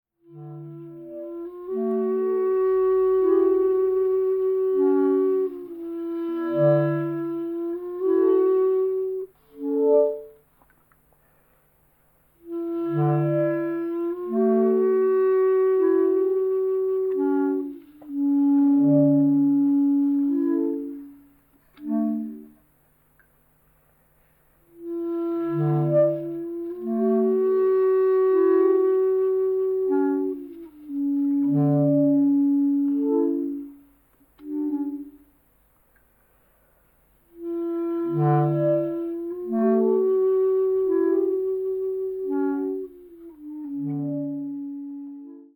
クラリネットだけでため息のような音楽を奏でる